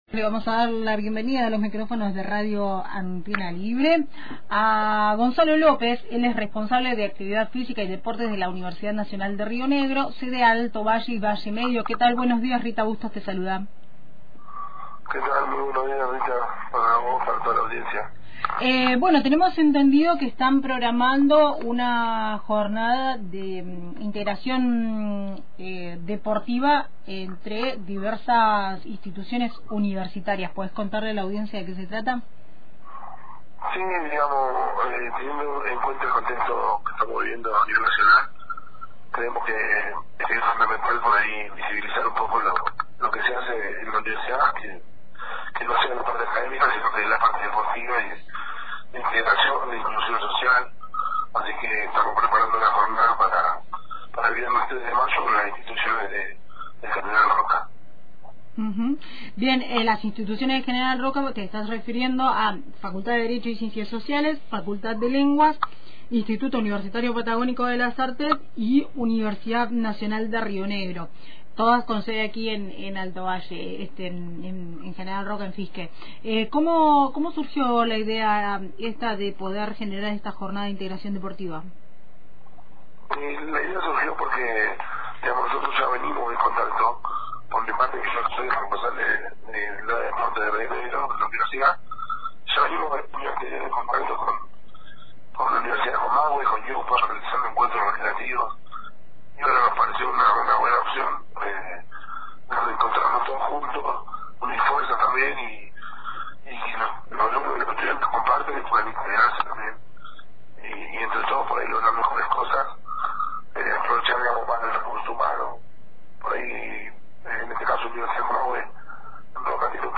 Para conocer más sobre esta propuesta, escuchá la entrevista completa